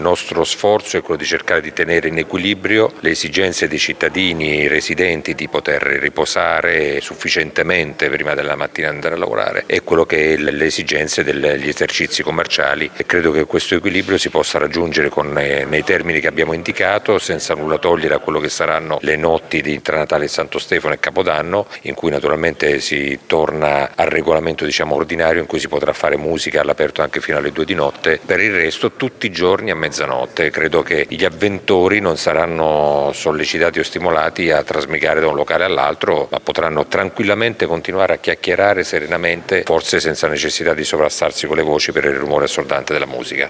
Il sindaco Massimo Mezzetti: